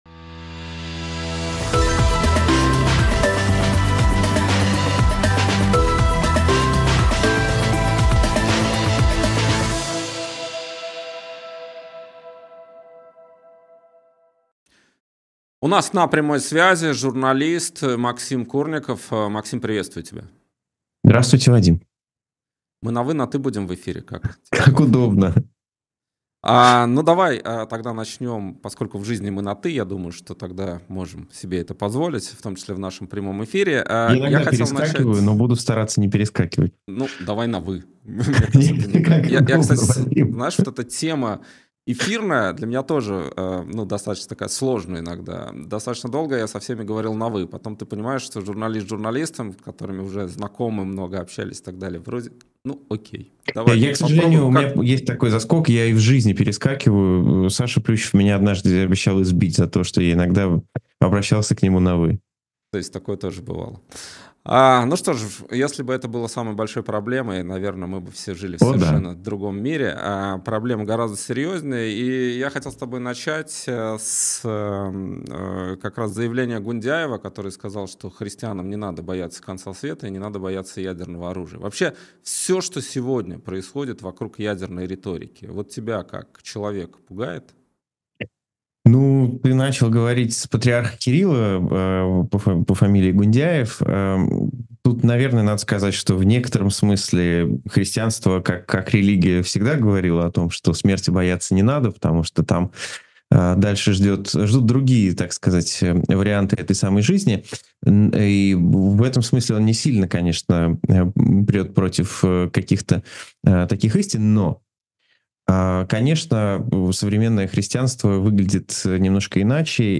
Интервью на канале «И грянул Грэм» 29 ноября 2024